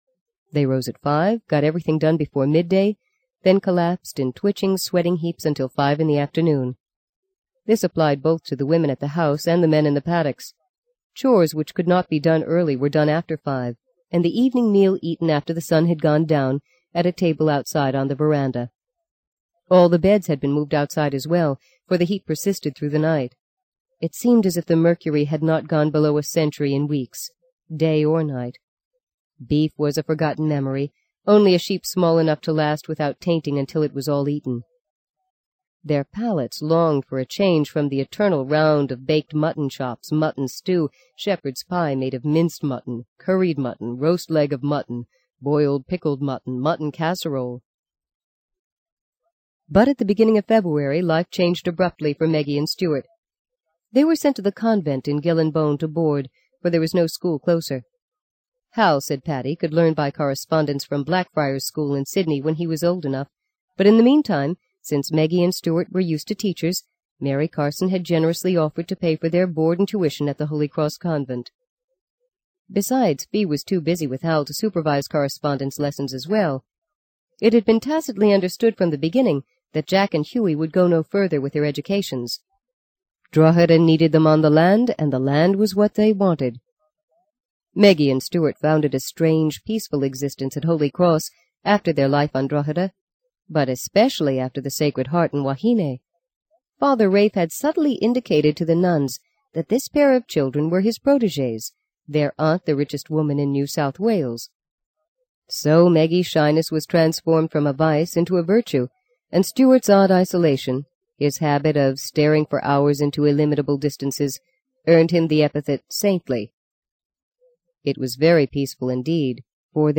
在线英语听力室【荆棘鸟】第四章 14的听力文件下载,荆棘鸟—双语有声读物—听力教程—英语听力—在线英语听力室